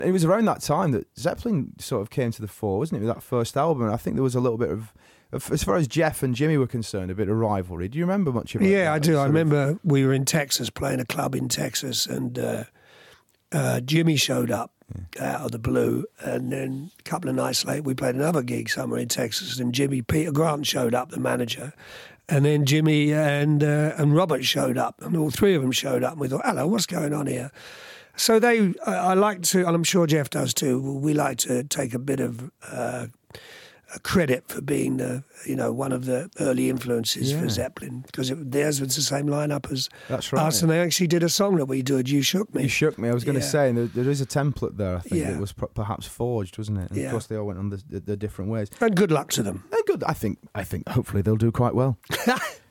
Rod Stewart tells Shaun Keaveny how he and Jeff Beck influenced Led Zeppelin.